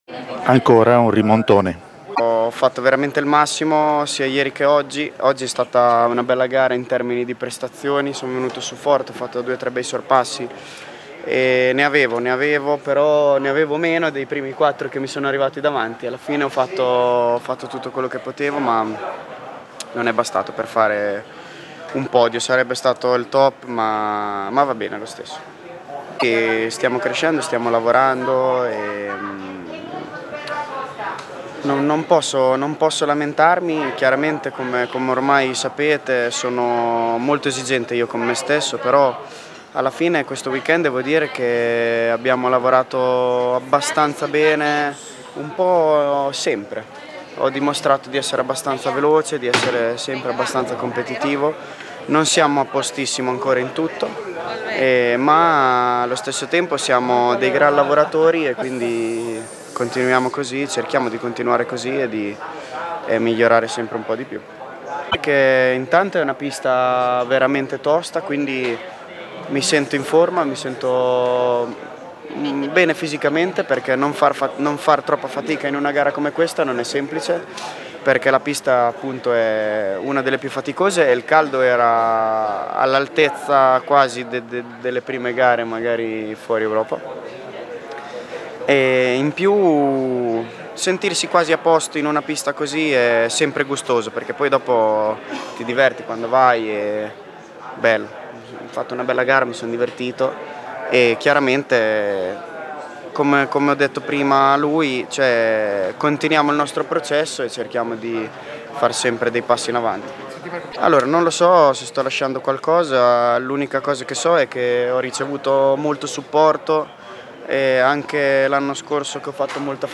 Marco Bezzecchi, al microfono